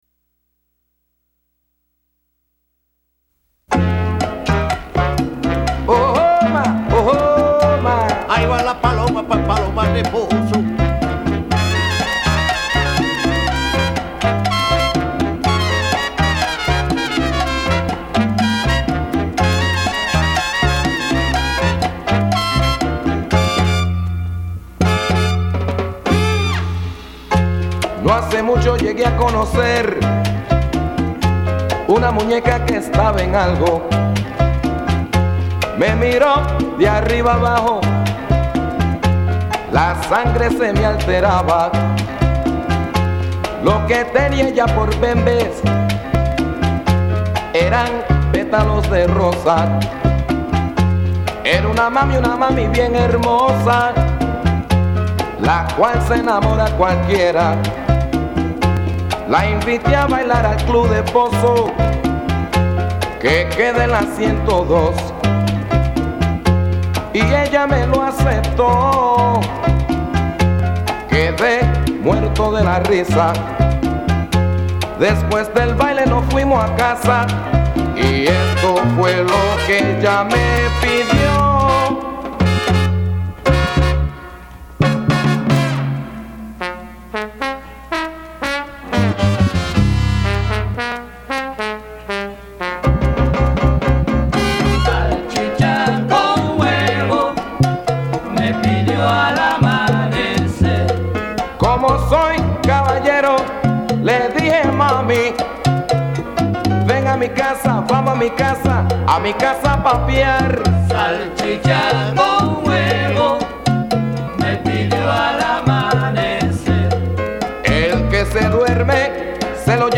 гуахира
(Соло духовой секции)